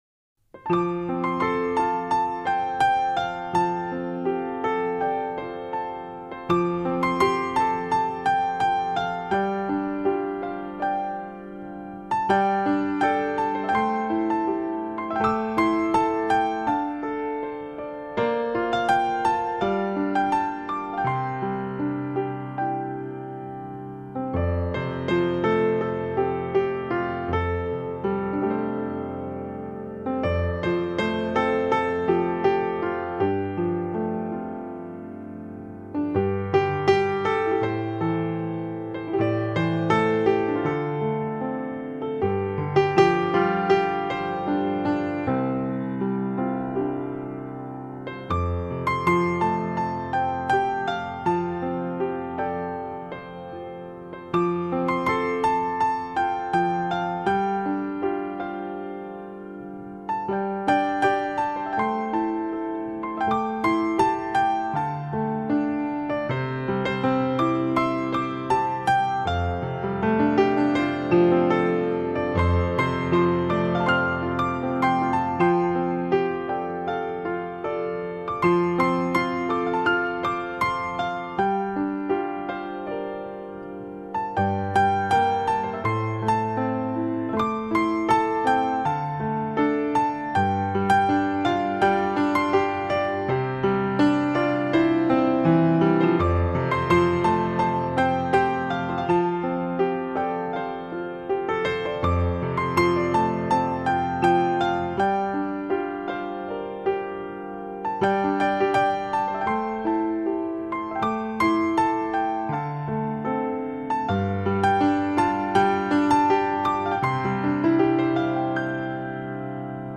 火红烂漫 清澄铿锵
飘逸灵动的名琴华丽音色
声音靓绝 自然传真
真正示范级发烧三角名琴录音